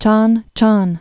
(chän chän)